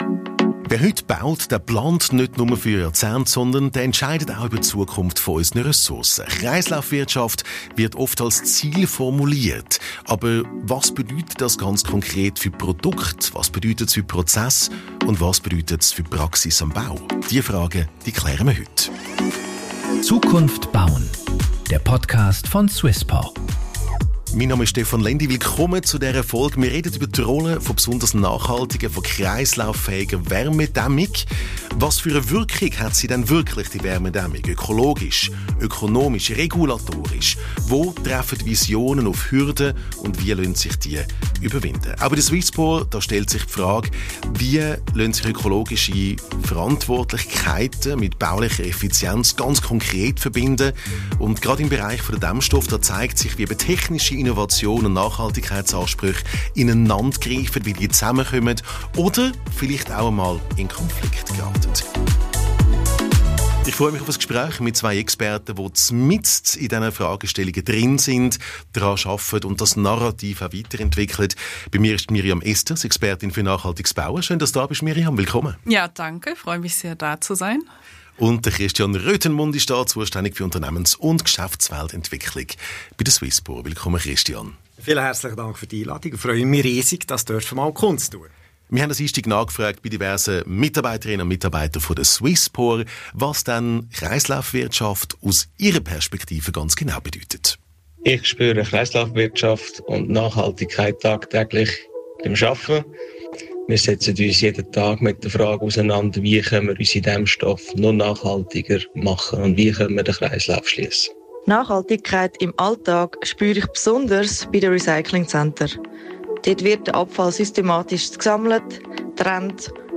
Es ist ein praxisnahes Gespräch über Materialströme, Energieeffizienz, Recycling und Reuse – aber auch über Marktmechanismen, Bewertungsmodelle und die Frage, wie nachhaltige Lösungen vom Leuchtturmprojekt in die Breite kommen.